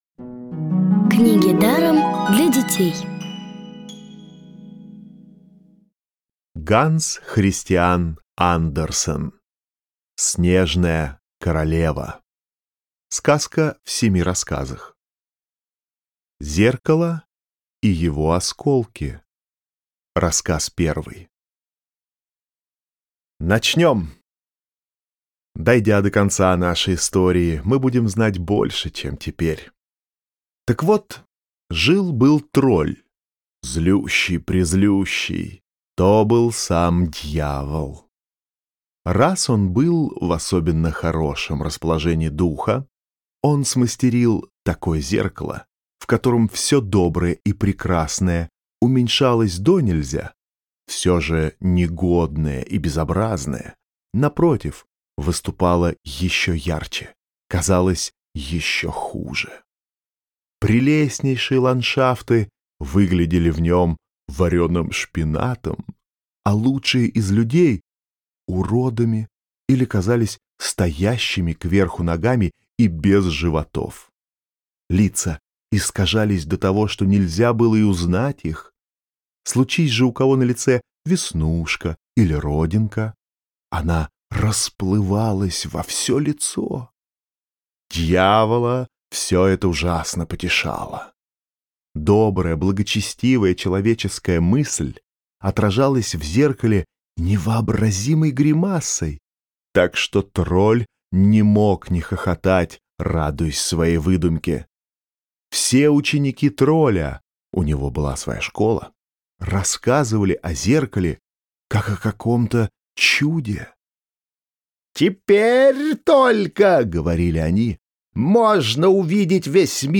Аудиокнига бесплатно «Снежная королева» от Рексквер. Сказки Андерсена.
Аудиокниги онлайн – слушайте «Снежную королеву» в профессиональной озвучке и с качественным звуком.